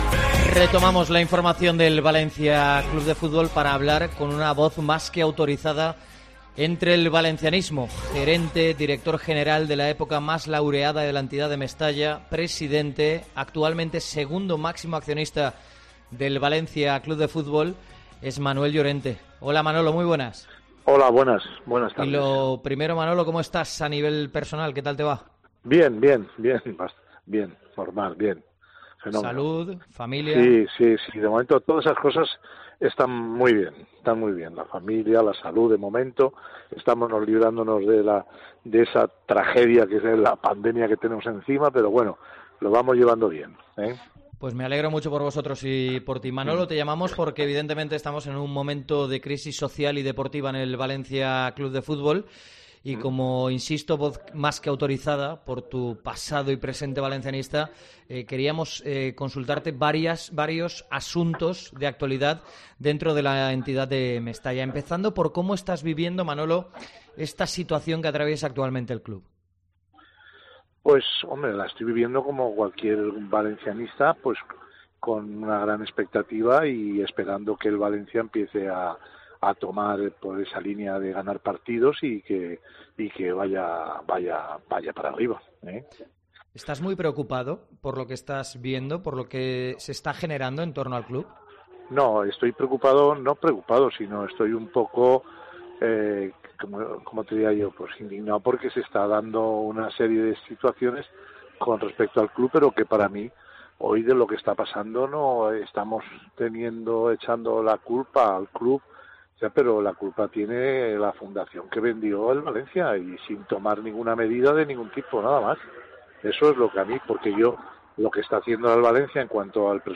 AUDIO. Entrevista a Manuel Llorente en Deportes COPE + Valencia